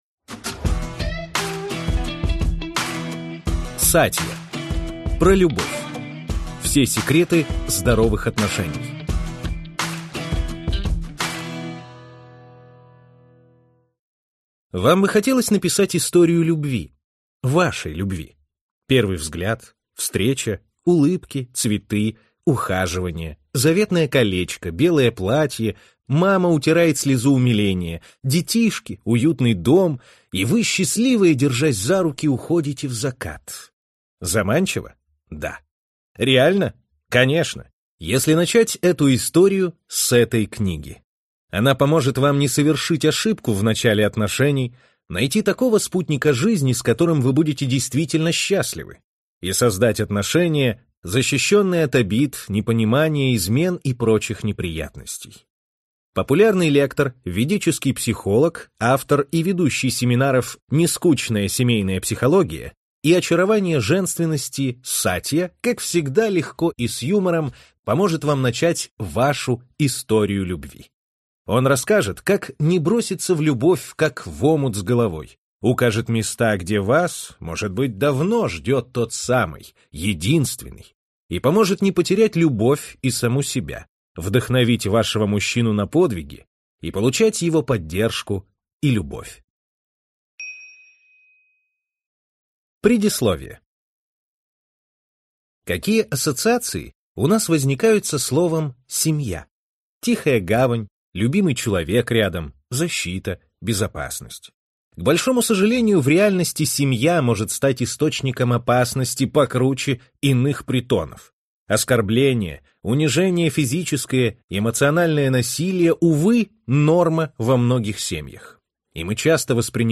Аудиокнига PRO любовь. Все секреты здоровых отношений | Библиотека аудиокниг